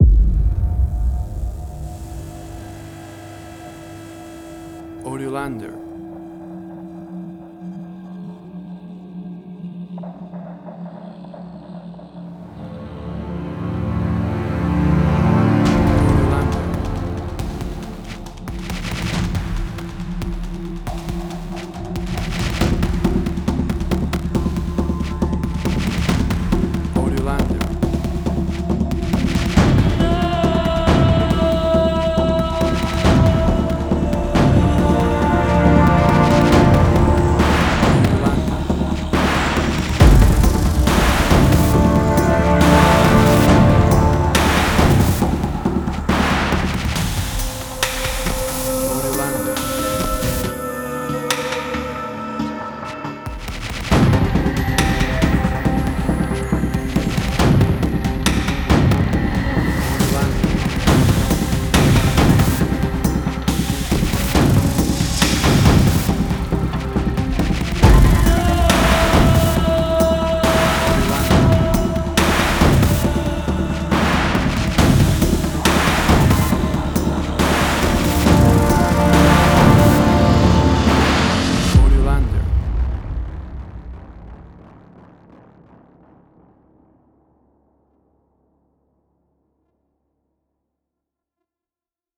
Suspense, Drama, Quirky, Emotional.
WAV Sample Rate: 16-Bit stereo, 44.1 kHz
Tempo (BPM): 138